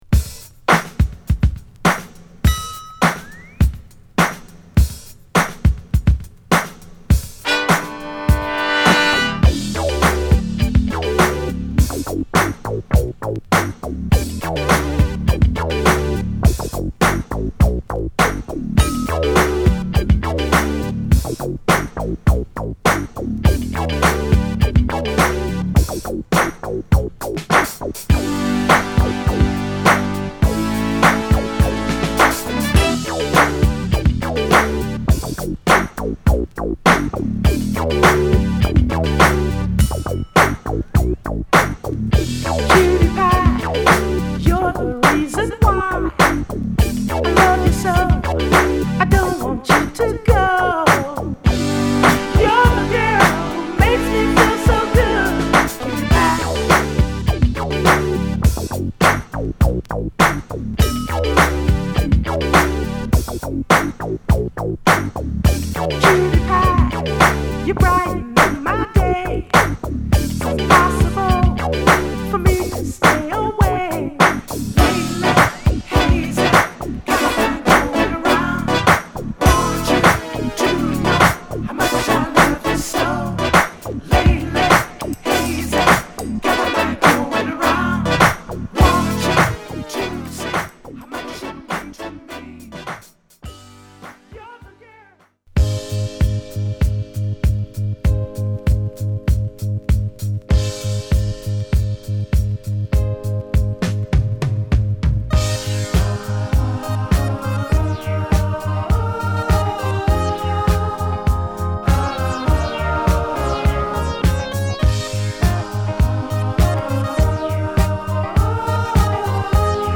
シンセが気持良く絡むミディアムダンサーなタイトル曲